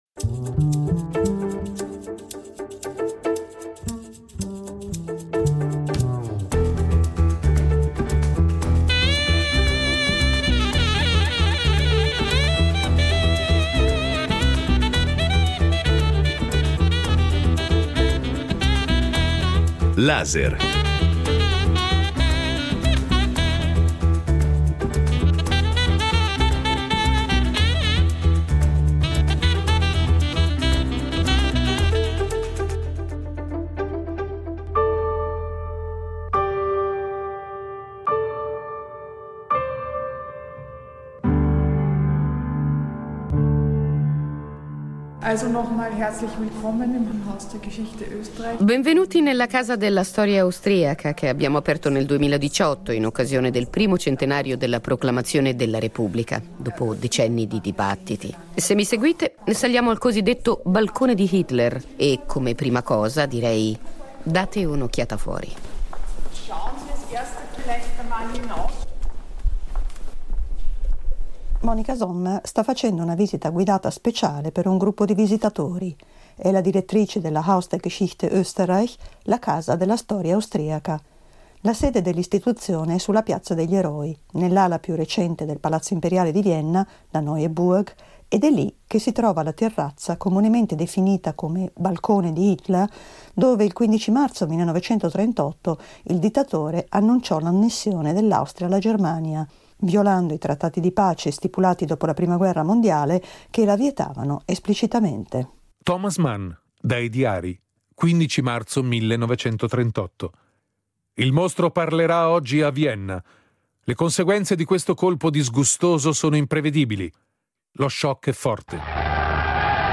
Come quel balcone, in Europa vi sono molti luoghi controversi e marchiati da uno stigma indelebile, e il caso viennese può essere un esempio paradigmatico della rielaborazione di memorie scomode. Con l’aiuto di storici, di documenti sonori, di commenti di allora e di opinioni di gente comune del nostro oggi